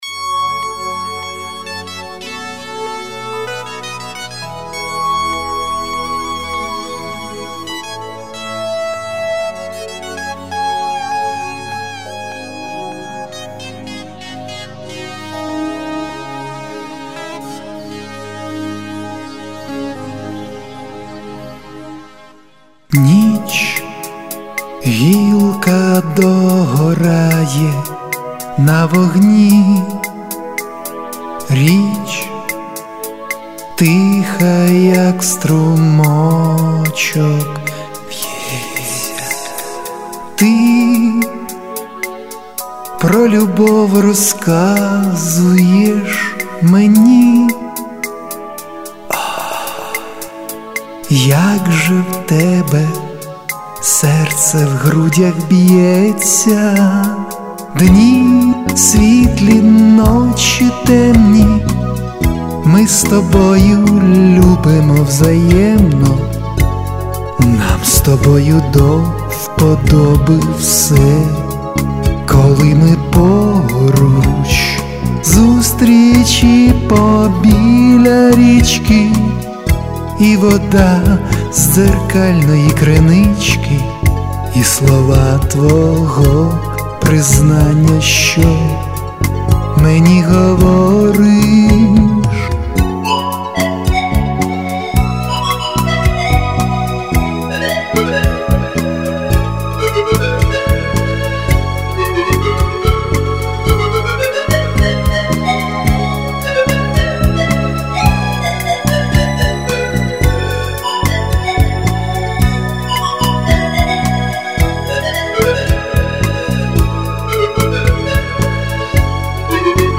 Рубрика: Поезія, Авторська пісня
Романтика... 16 16 16